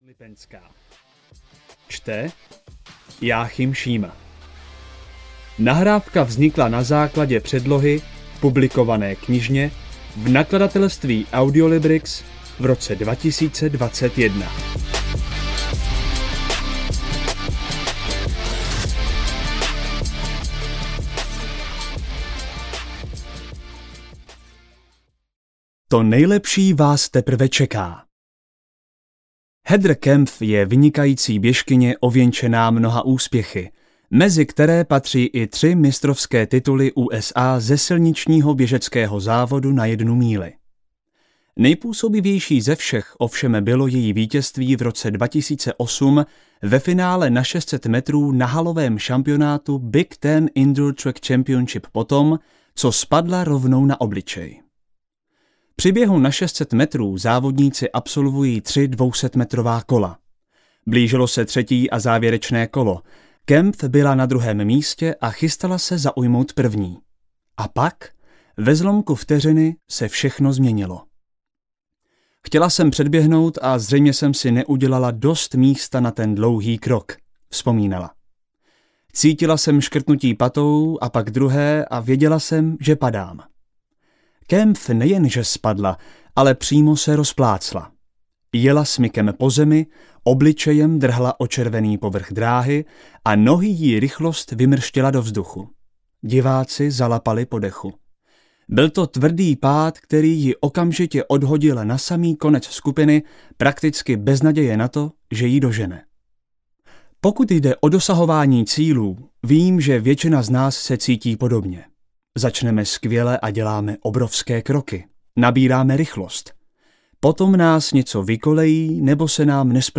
Nejlepší rok vašeho života audiokniha
Ukázka z knihy